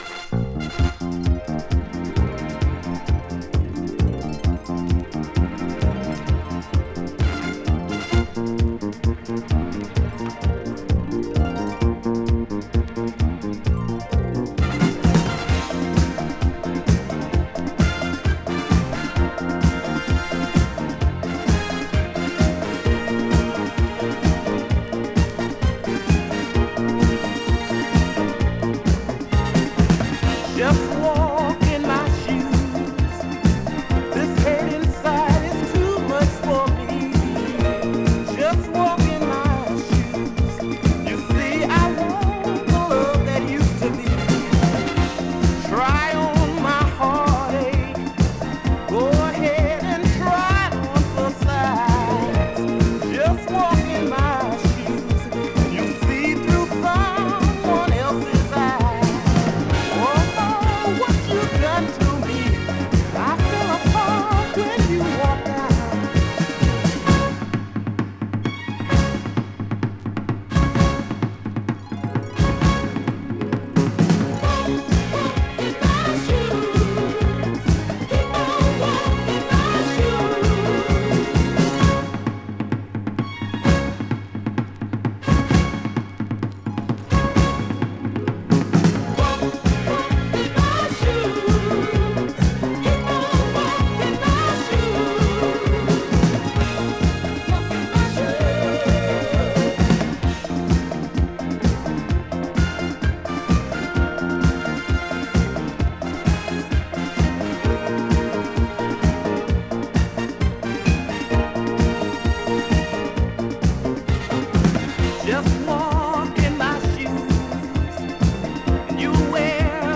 HI-NRG Disco!
【DISCO】【BOOGIE】